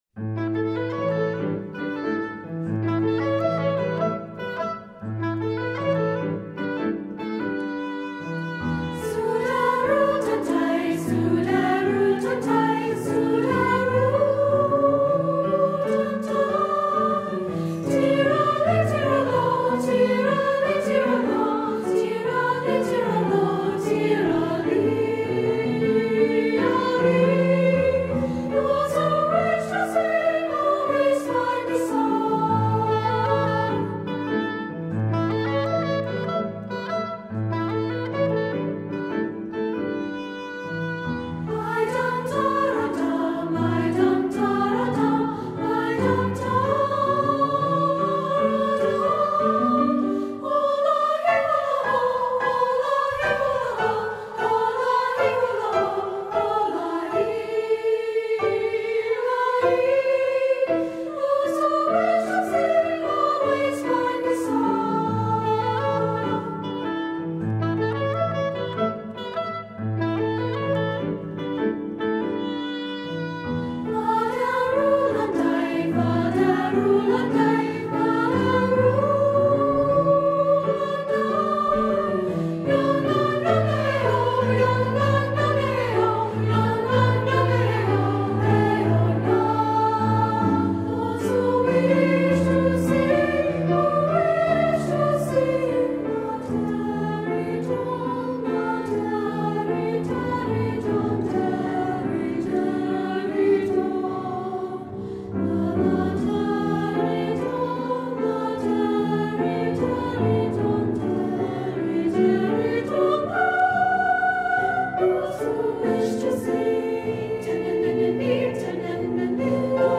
SSA, oboe, piano